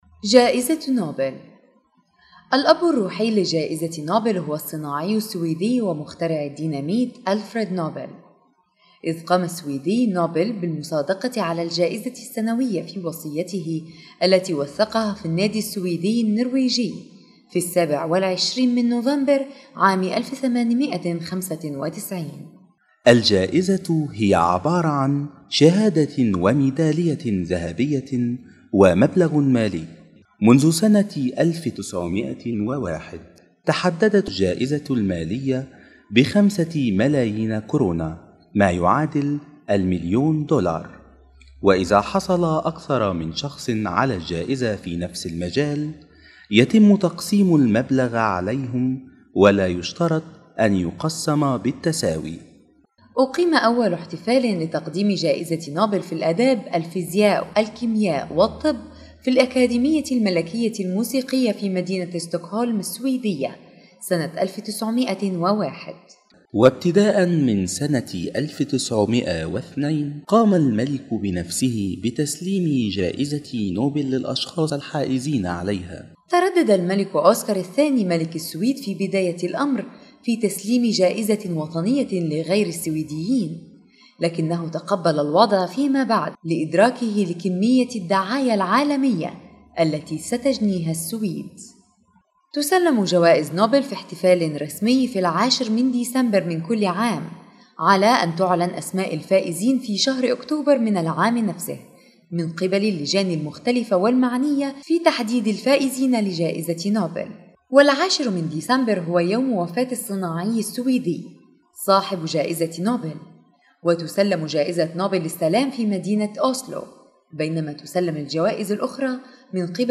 Son de la Connaissance ...ήχο της γνώσης .. sólida de conocimientos الشيوعية أداء صوتي